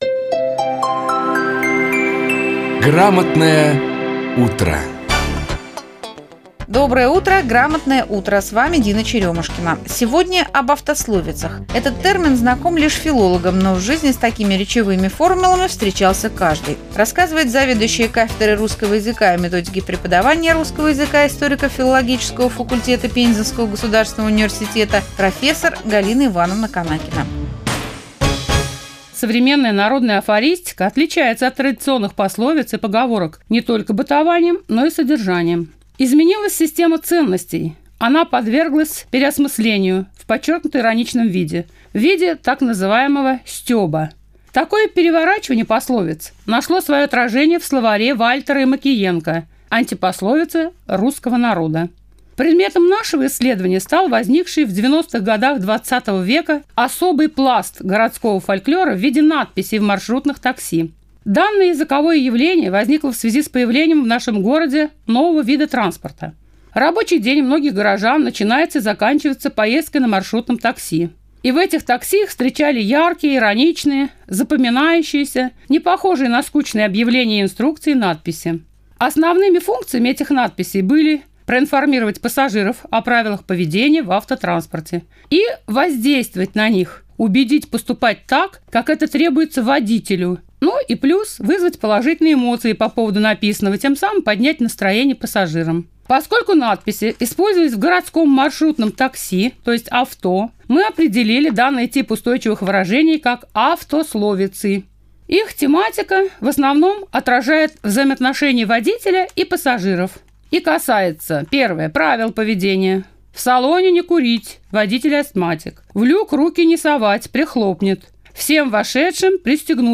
выступила в эфире «Радио России из Пензы»